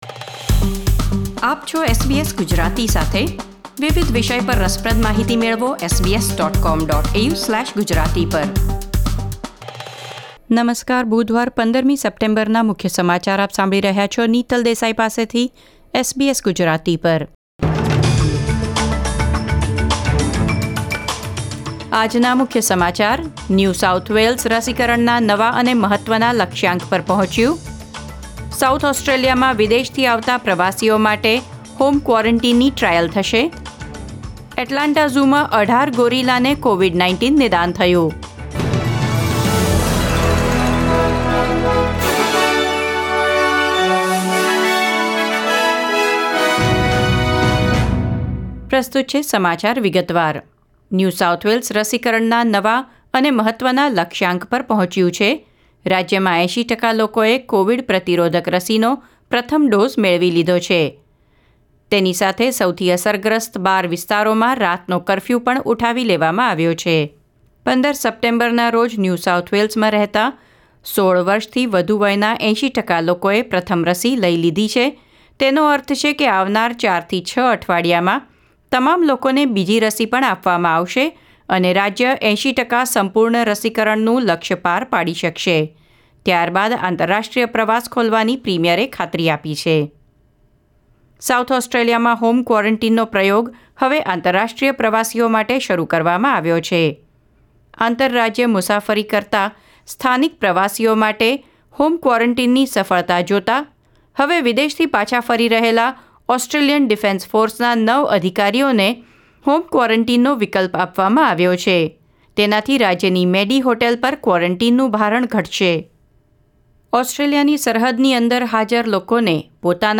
SBS Gujarati News Bulletin 15 September 2021